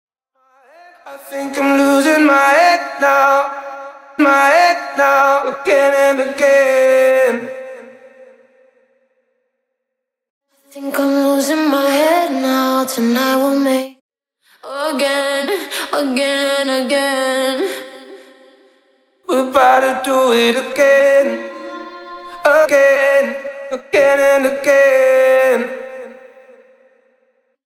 DEMO FOR THE ACOUSTIC VERSION HERE :